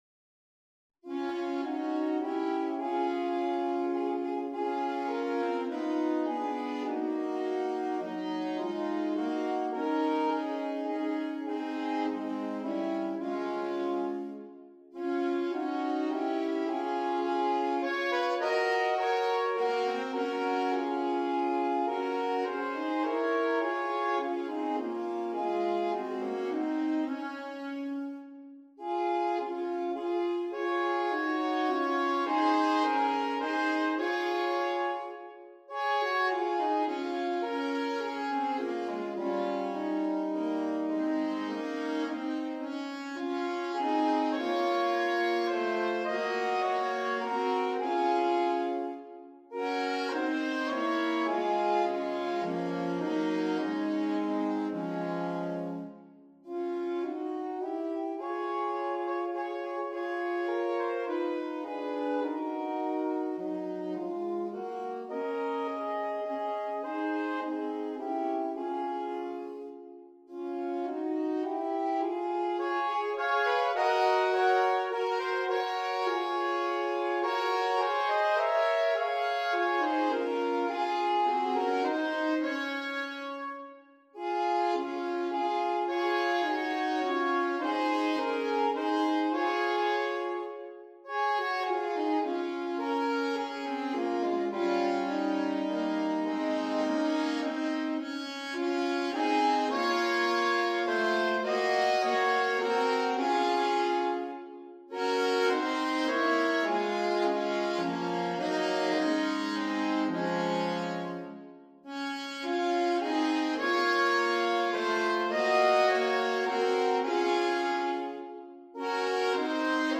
arranged for alto saxophone trio